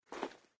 在薄薄的雪地上脚步声－右声道－YS070525.mp3
通用动作/01人物/01移动状态/02雪地/在薄薄的雪地上脚步声－右声道－YS070525.mp3
• 声道 立體聲 (2ch)